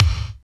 28.05 KICK.wav